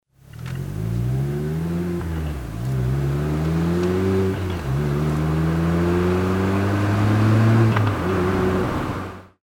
普通のインナーサイレンサー
ちなみに運転席から収録した走行音だとこんな感じです。
普通の走行音
低音の強さは、今回の36パイが個人的にはベストですね。